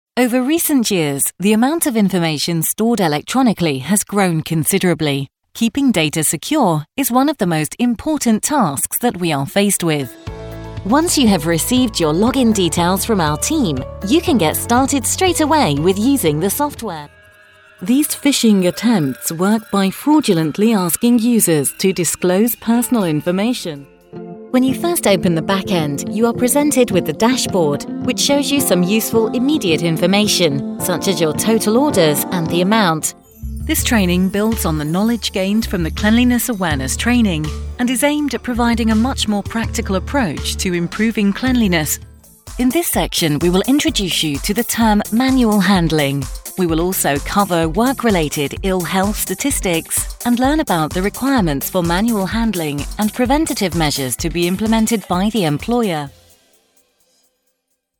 Apprentissage en ligne
An experienced and versatile British female voice over, recording for clients around the globe from my professional home studio in the UK.
My voice has been described as warm with gentle gravitas – I love performing deep and modulated narrations – though I can definitely switch things up for more fun and bubbly reads.
Home studio with purpose-built floating isolation booth